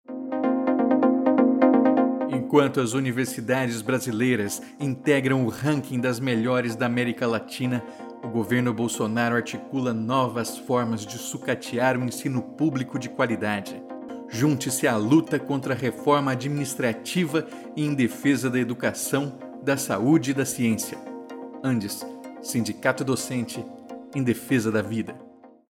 Também estamos entrando com inserções de áudio em redes sociais, com os spots abaixo, realizados pela equipe de comunicação das seções gaúchas do ANDES-SN, que reúne Adufpel, Aprofurg, Sedufsm, Sesunipampa, Seção da UFRGS e SindoIF, com apoio da Regional RS.
Spot2_PEC32-1.mp3